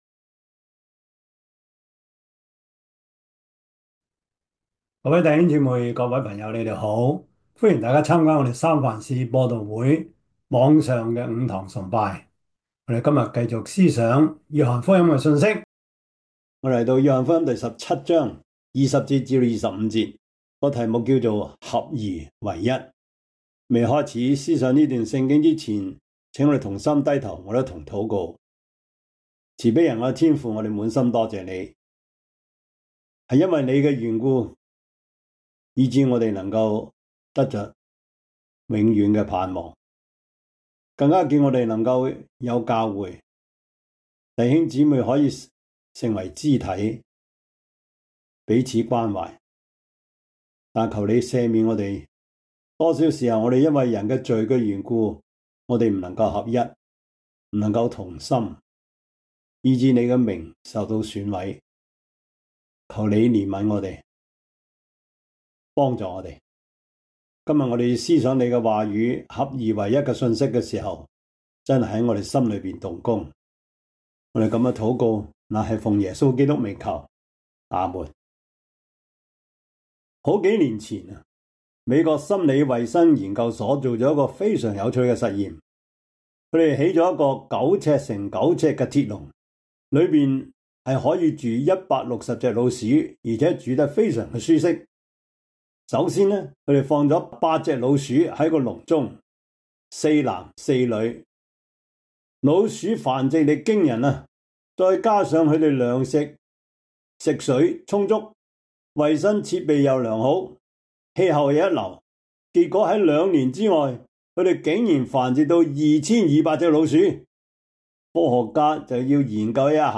約翰福音 17:20-25 Service Type: 主日崇拜 約翰福音 17:20-25 Chinese Union Version